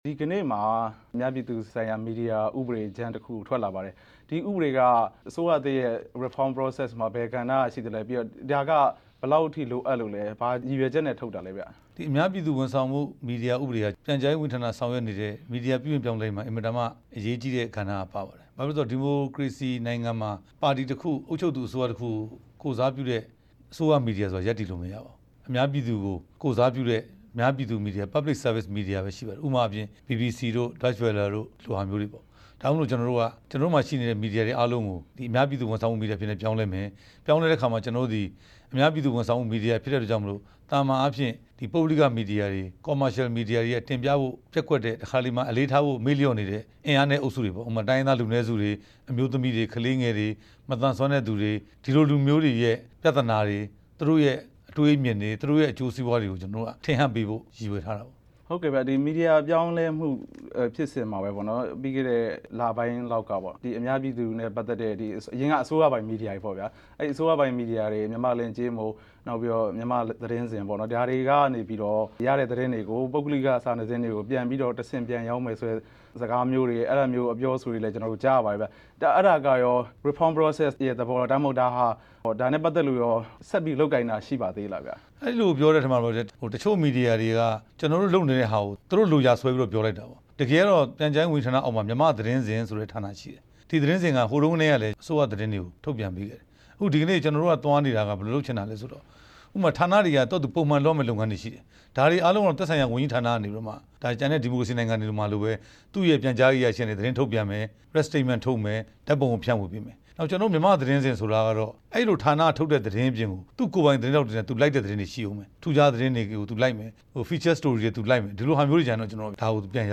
အမေရိကန်ရောက် ပြန်ကြားရေးဒုဝန်ကြီး ဦးရဲထွဋ် နဲ့ မေးမြန်းချက်